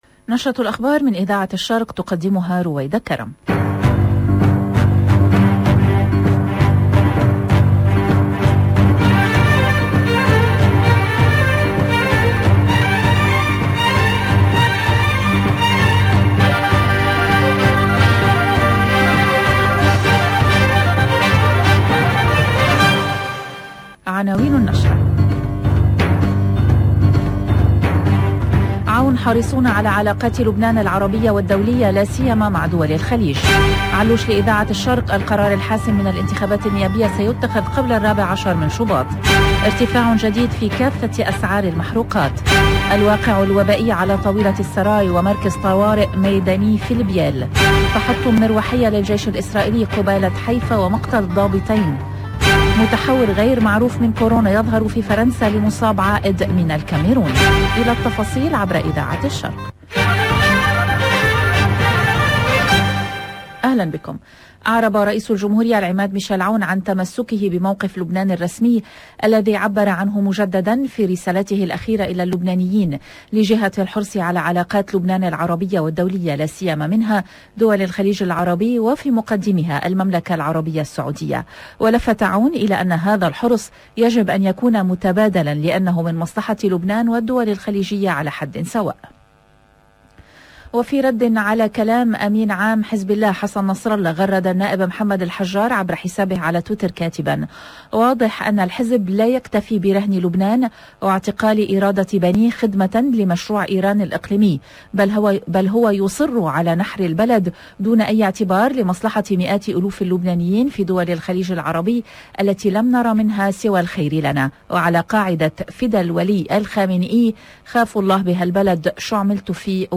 LE JOURNAL DU LIBAN EN LANGUE ARABE DE 13H30 DU 4/1/2022